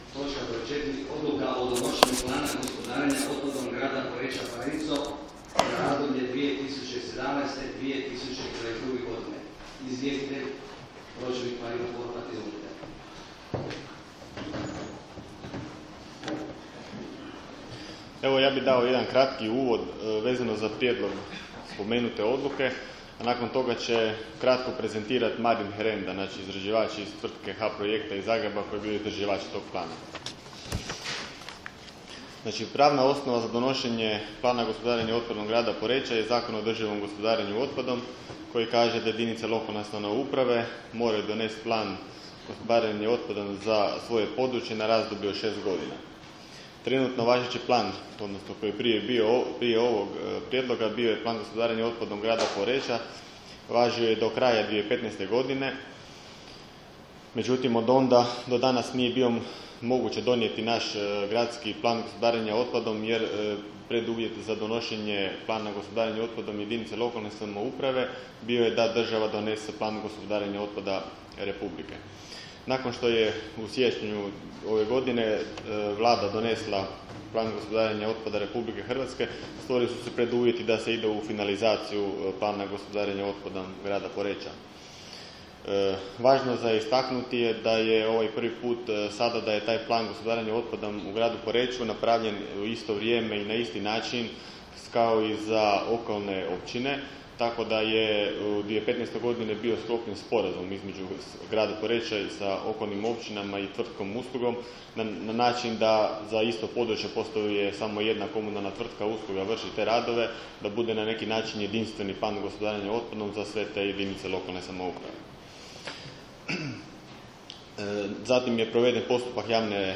Sa druge sjednice Gradskog vijeća Grada Poreča